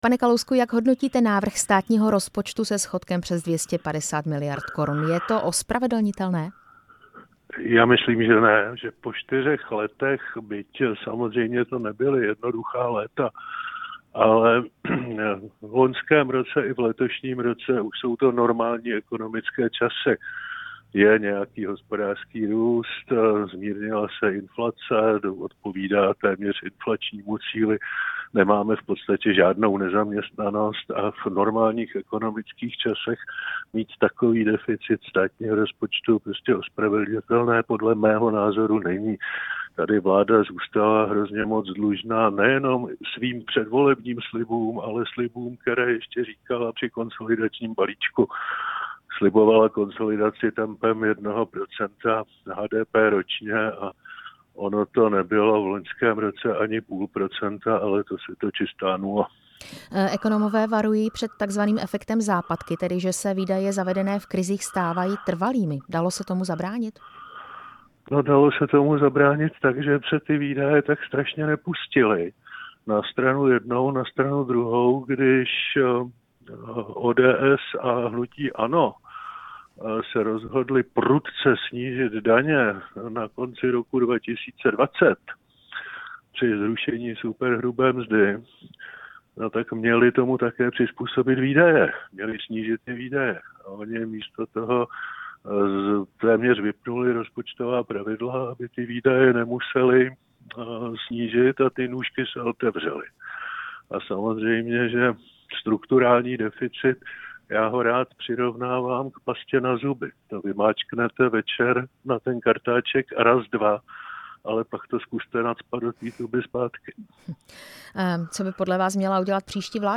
Rozhovor s exministrem financí Miroslavem Kalouskem